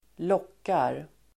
Uttal: [²l'åk:ar]